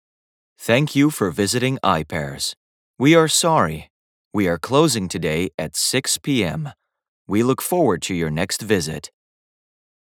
キャラクターボイスに特に情熱を持ち、活気に満ちた声と幅広い音域を活かして、 ビデオゲーム、アニメ、オーディオドラマなどで様々なキャラクターに命を吹き込んでいる。
ナレーターランクⅢ（男性）